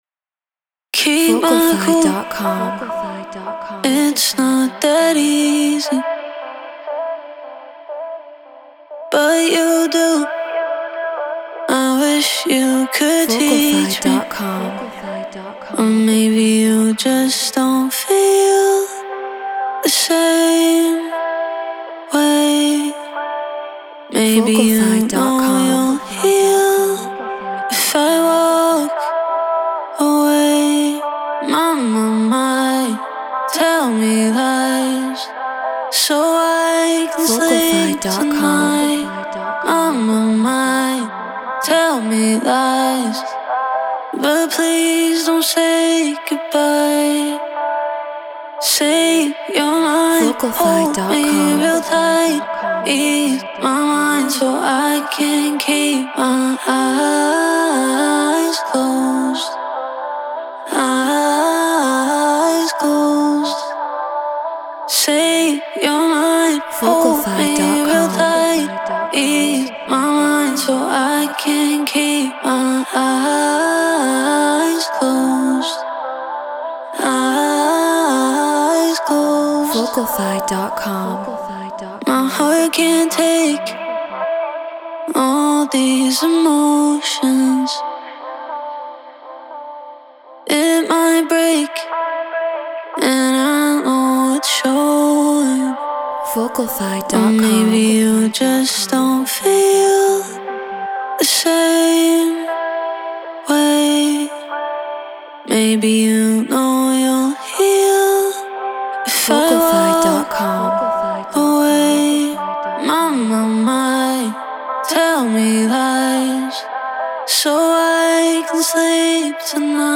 Afro House 118 BPM Cmaj